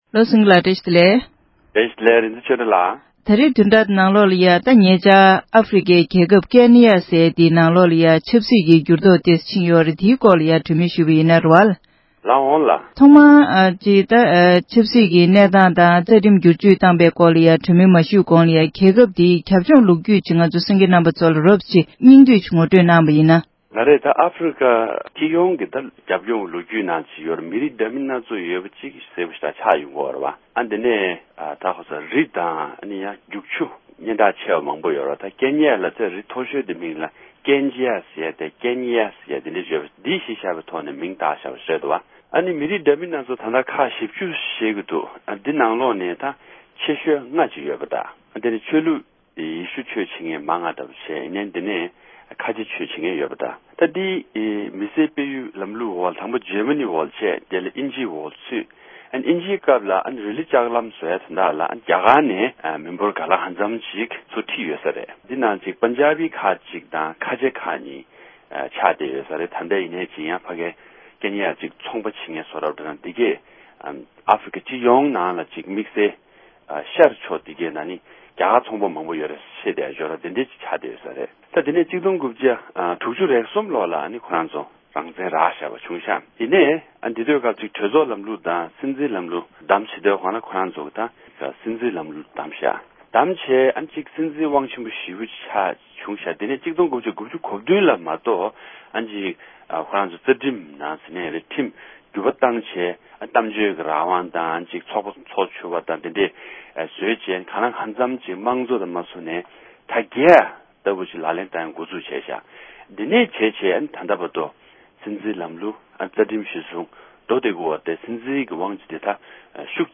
ཨཕ་རི་ཀའི་ཀེན་ནི་ཡ་ཞེས་པའི་རྒྱལ་ཁབ་དེའི་ཆབ་སྲིད་ཀྱི་གནས་སྟངས་དང་ཉེ་ཆར་རྩ་ཁྲིམས་གསར་བཟོ་བྱས་པའི་གནད་དོན་གླེང་མོལ།